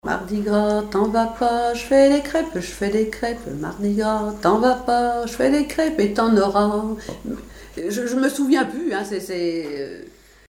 Chants brefs
circonstance : carnaval, mardi-gras
Pièce musicale inédite